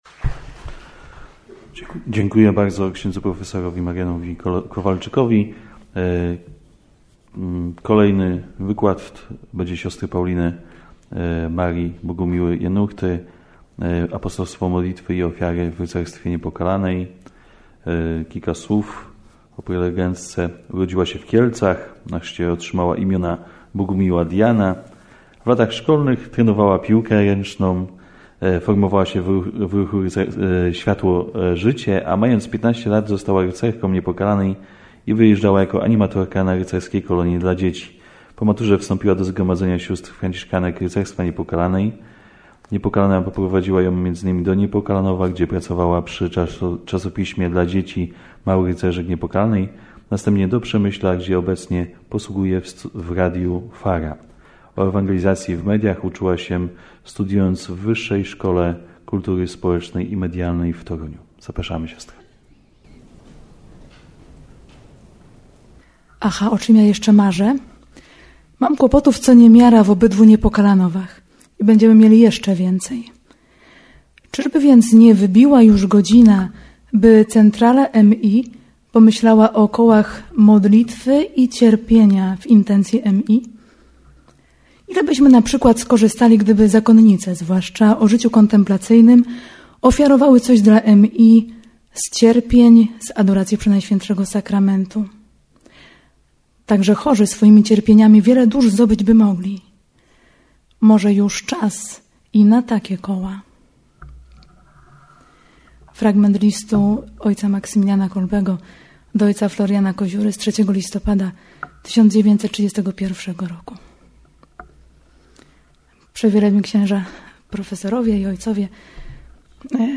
Na zakończenie Jubileuszu 100-lecia Rycerstwa Niepokalanej w Niepokalanowie odbyła się Ogólnopolska Konferencja naukowa pod hasłem: "Rycerstwo Niepokalanej